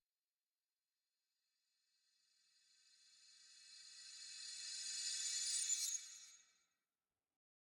Minecraft Version Minecraft Version latest Latest Release | Latest Snapshot latest / assets / minecraft / sounds / ambient / nether / warped_forest / enish1.ogg Compare With Compare With Latest Release | Latest Snapshot